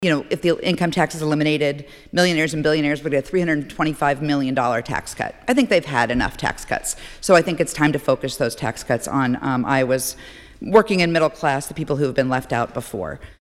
House Minority Leader Jennifer Konfrst, a Democrat from Windsor Heights, says complete elimination of the income tax would forever eliminate about half of the state’s total revenue.